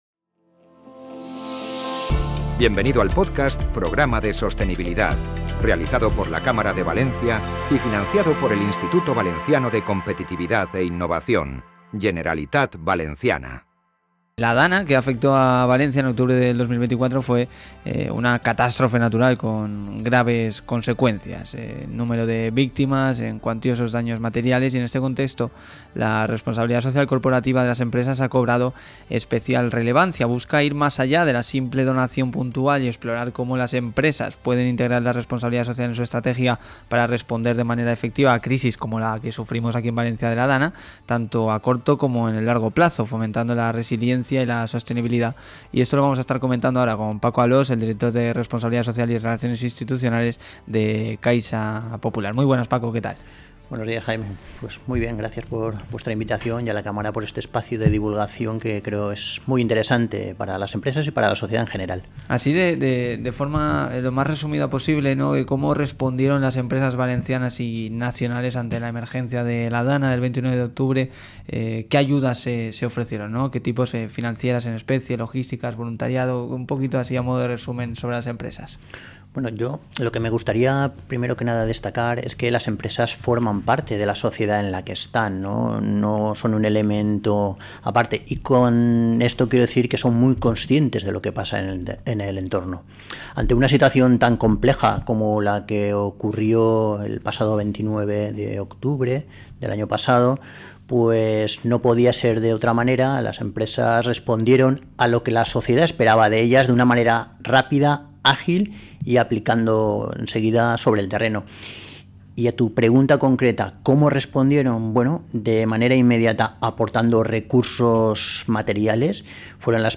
Entrevista Caixa Popular